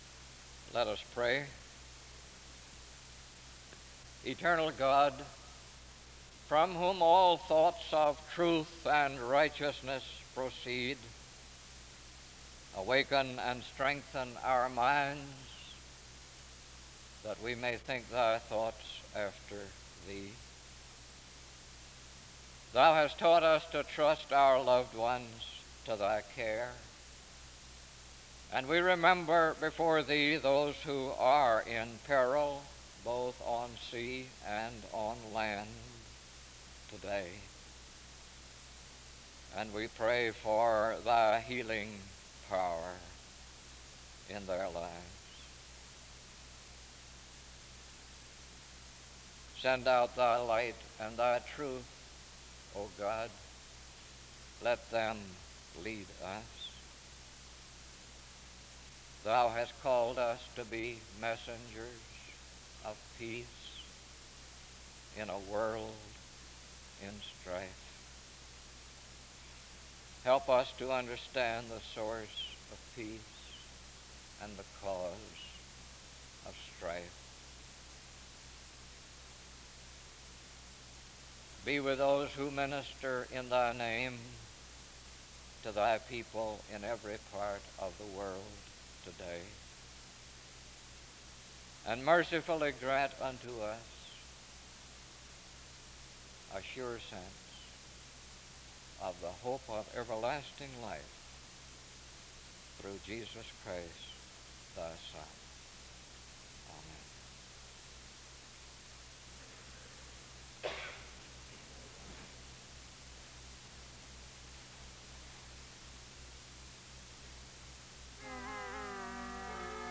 The service begins with a prayer (0:00-1:49). After which, there is a period of singing (1:50-4:58).